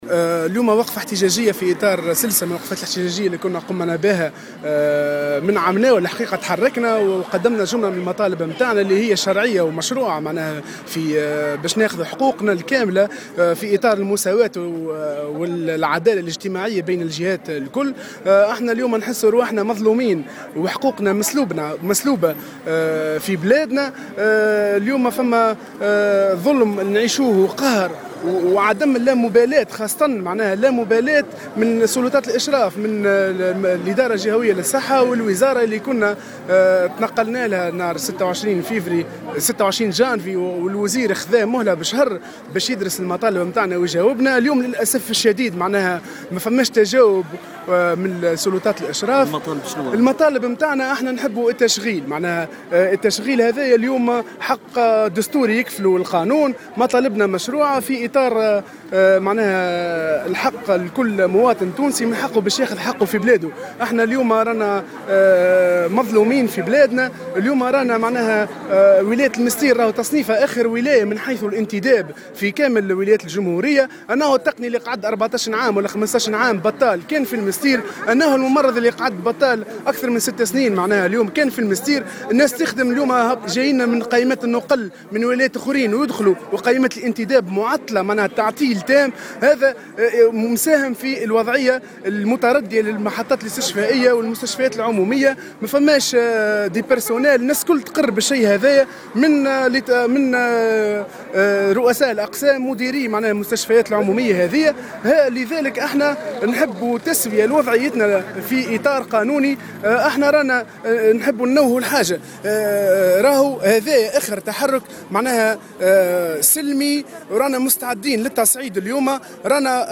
Dans une déclaration accordée au correspondant de Jawhara FM